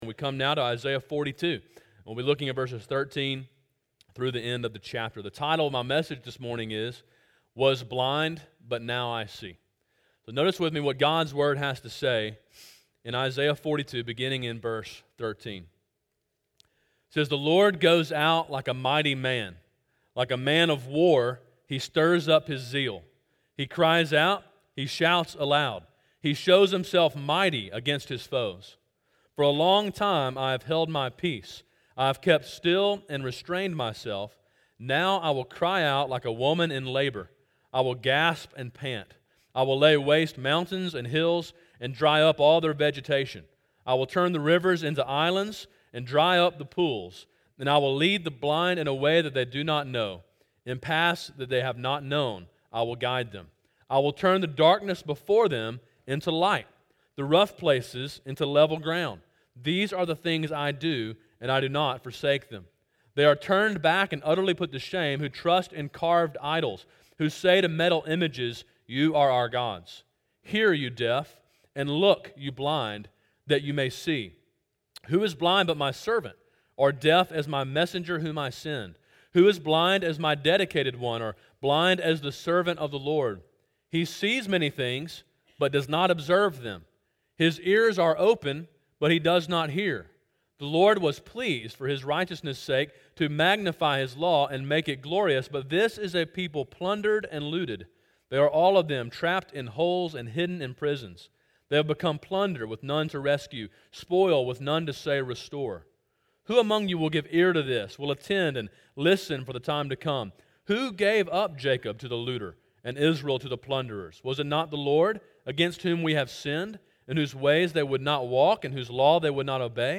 Sermon: “Was Blind, but Now I See” (Isaiah 42:13-25)